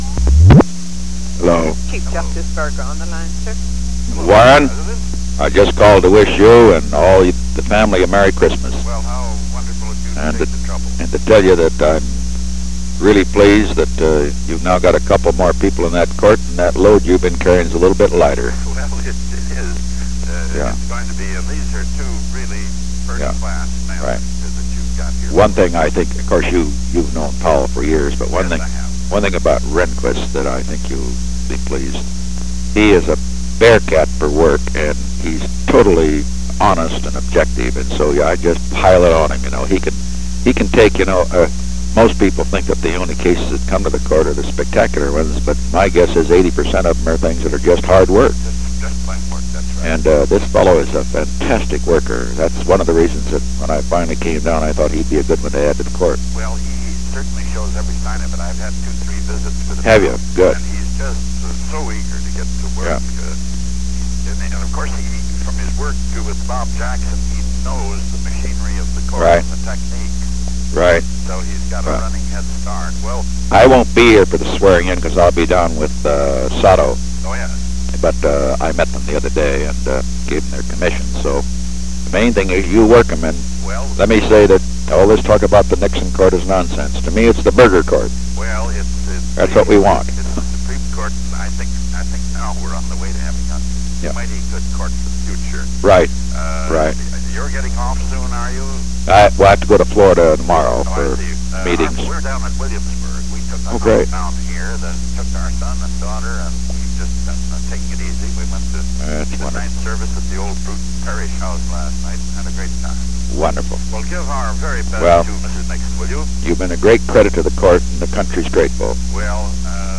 Secret White House Tapes
Conversation No. 17-76
Location: White House Telephone
The President talked with Warren E. Burger.